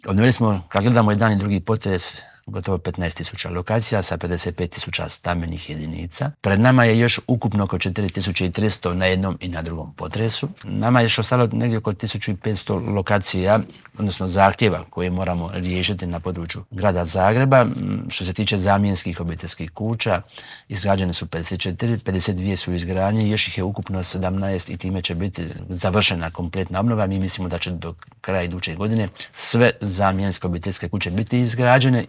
ZAGREB - U nedjelju je obilježena šesta godišnjica zagrebačkog potresa, što se još treba napraviti po pitanju poslijepotresne obnove u Intervjuu tjedna Media servisa pitali smo potpredsjednika Vlade i ministra prostornog uređenja, graditeljstva i državne imovine Branka Bačića.